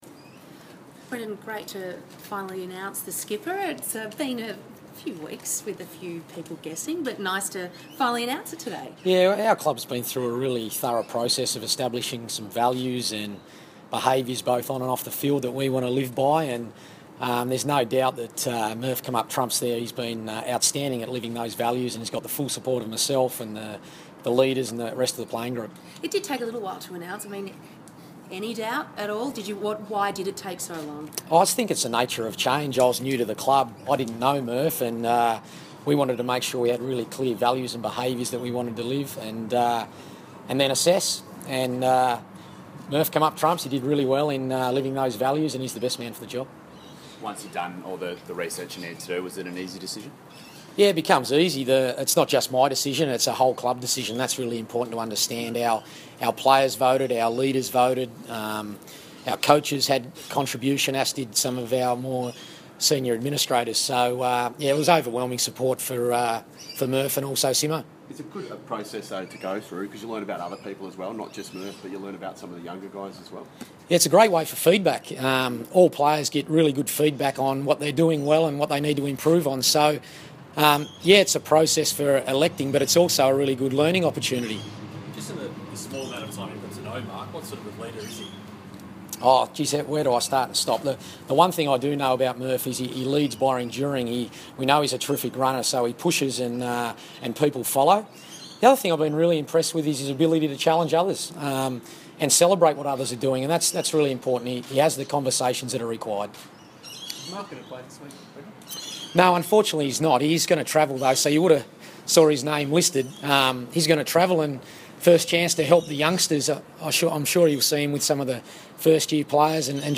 Brendon Bolton press conference - February 16